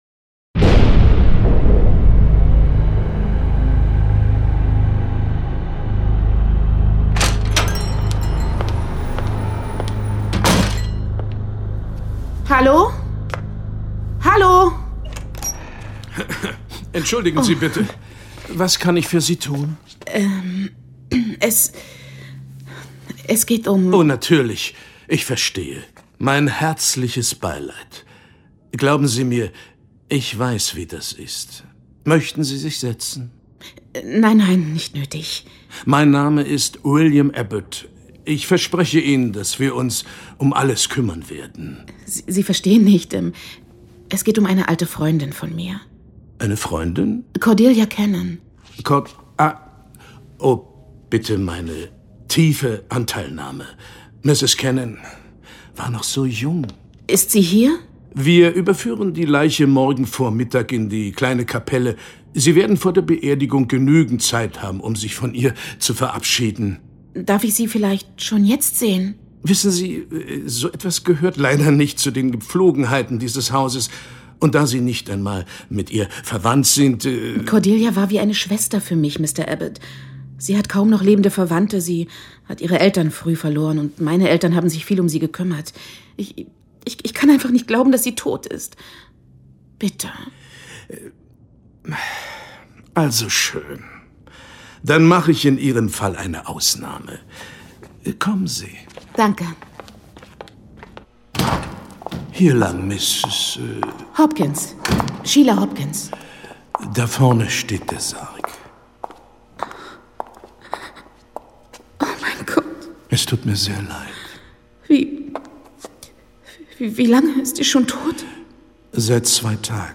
John Sinclair Classics - Folge 8 Das Rätsel der gläsernen Särge. Hörspiel.